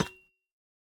Minecraft Version Minecraft Version latest Latest Release | Latest Snapshot latest / assets / minecraft / sounds / block / copper / step3.ogg Compare With Compare With Latest Release | Latest Snapshot
step3.ogg